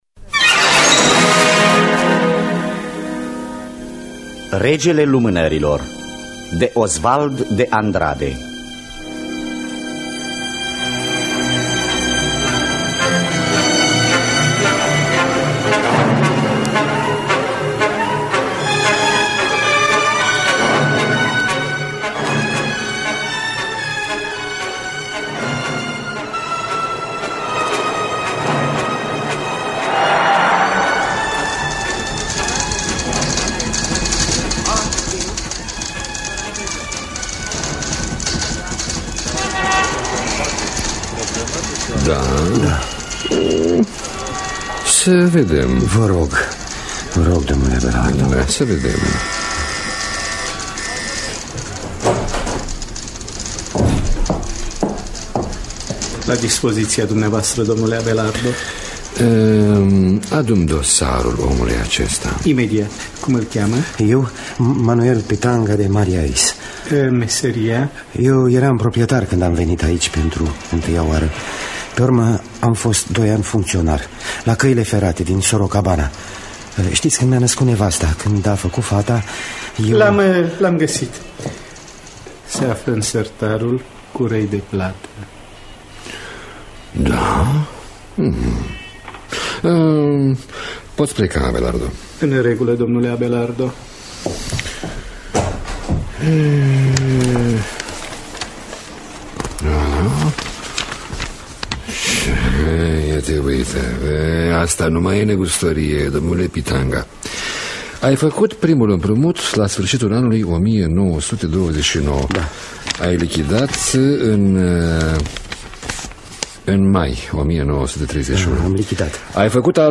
Adaptarea radiofonică.